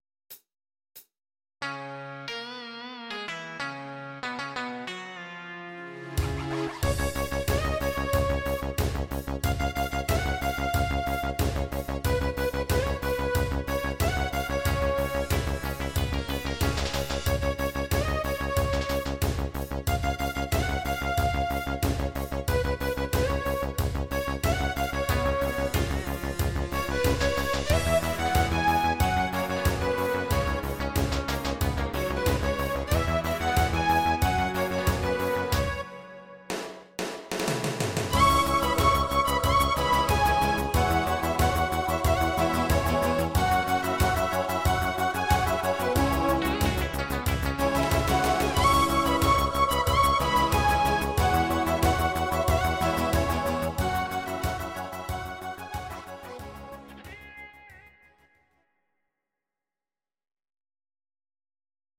These are MP3 versions of our MIDI file catalogue.
Your-Mix: Rock (2970)